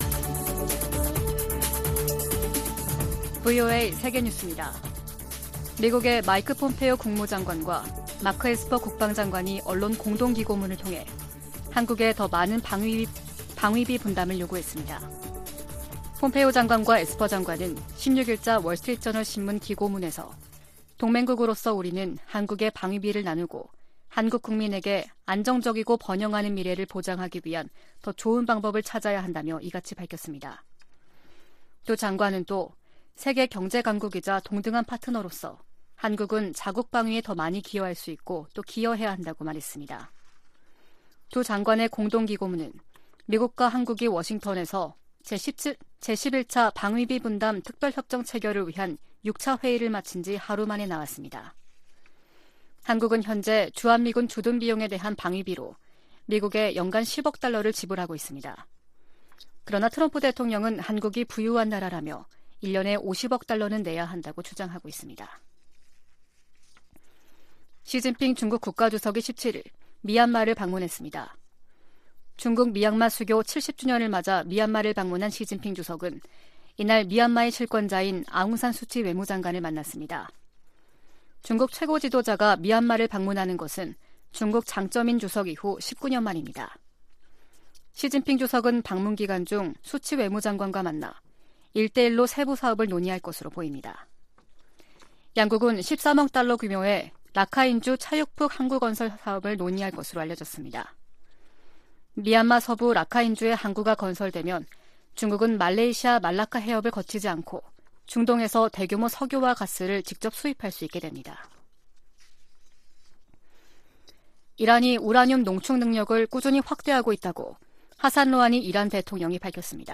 VOA 한국어 아침 뉴스 프로그램 '워싱턴 뉴스 광장' 2020년 1월 17일 방송입니다. 미국의 전직 관리들은 한국 정부가 밝힌 남북 협력사업이 미-한 간 조율 속에 진행되고 있을 것이라고 말했습니다. 미국 의회에 계류 중인 미-북 이산가족 상봉 법안의 통과를 촉구하는 목소리가 잇따르고 있습니다.